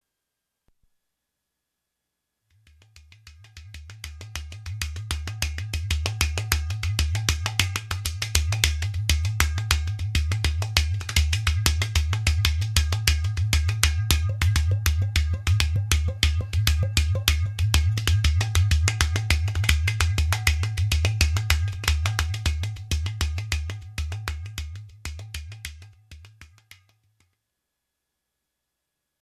Double Udu Drum
The Udu Drum is clay vessel with a hole in the side that is played by slapping your hand over the hole.
udu_drum1mp3.mp3